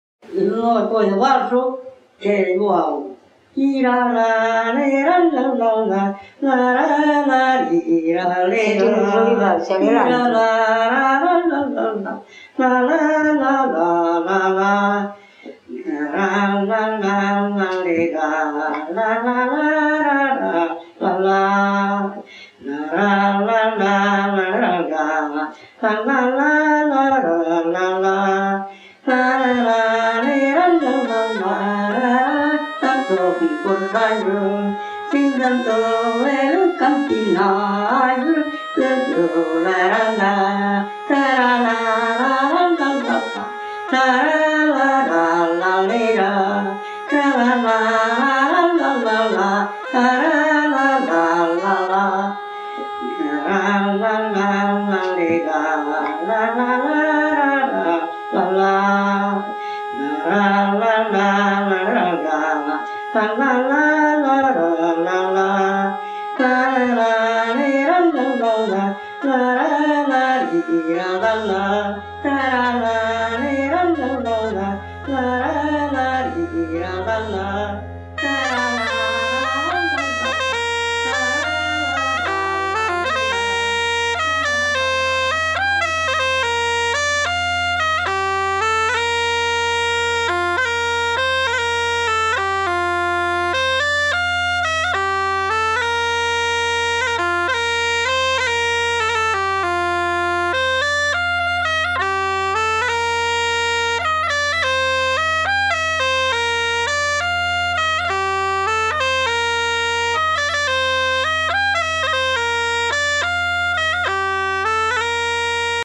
Descripteurs : musique-chant